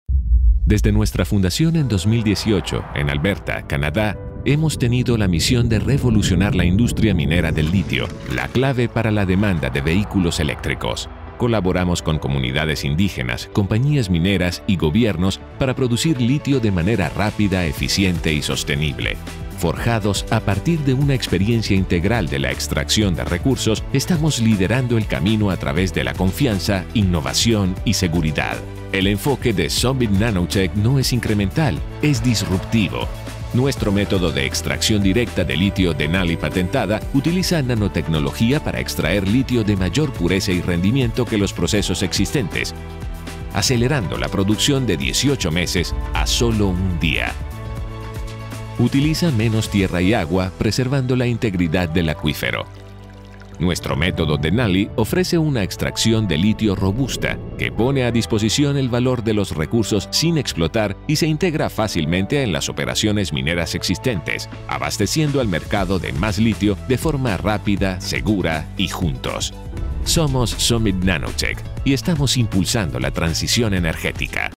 Female Voice Over, Dan Wachs Talent Agency.
Friendly, Warm, Conversational.
Corporate